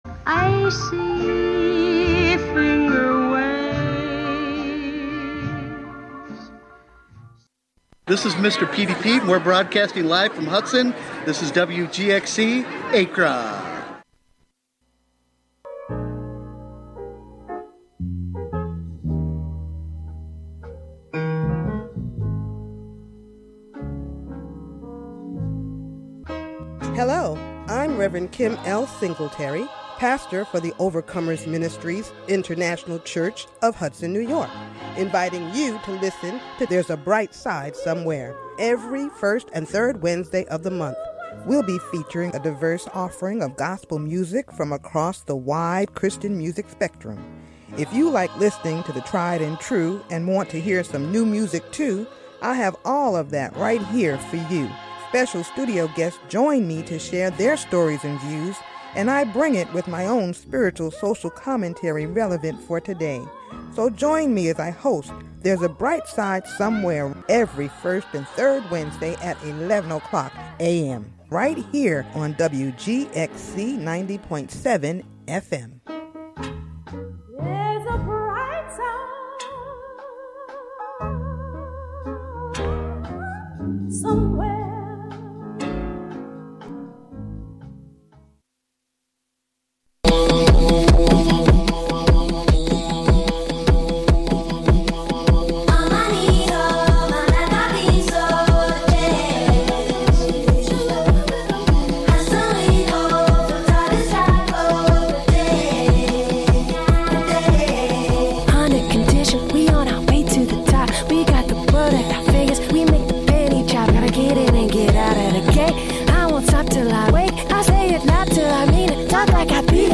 Youth Radio